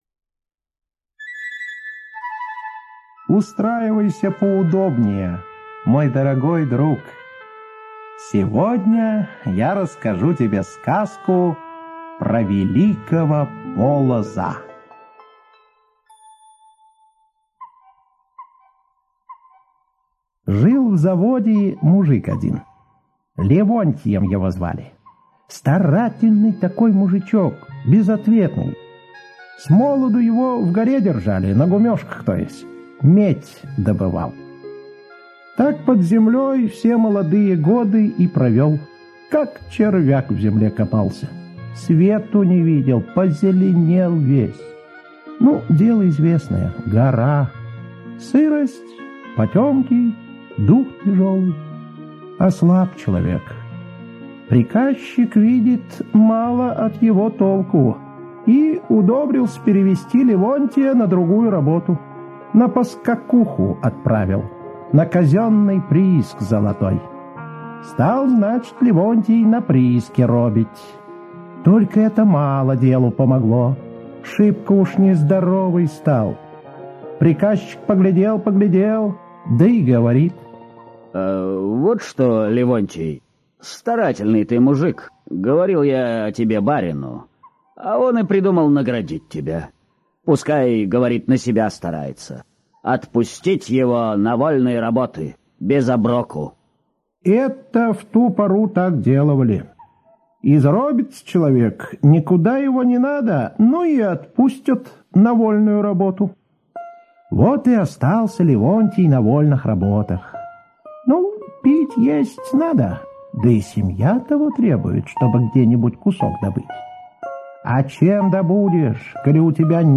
Слушайте Про Великого Полоза - аудиосказка Бажова П. Сказ про работника Левонтия, которому на старости лет разрешили на себя работать.